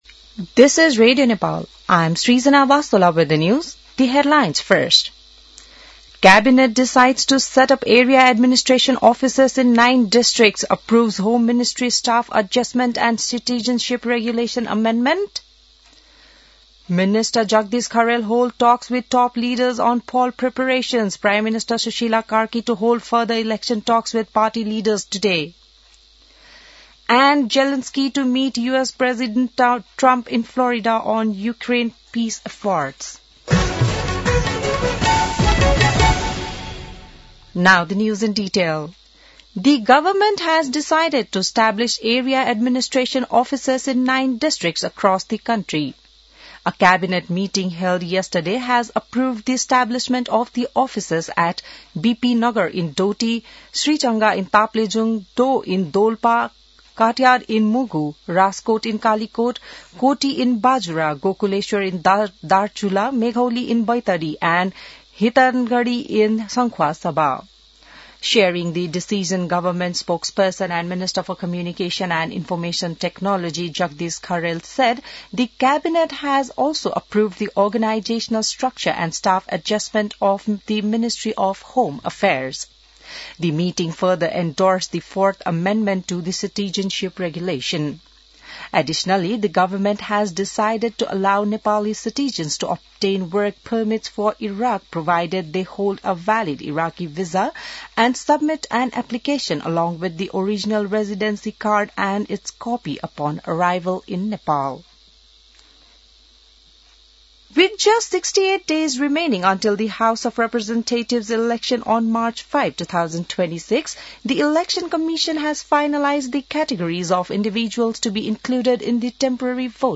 बिहान ८ बजेको अङ्ग्रेजी समाचार : १२ पुष , २०८२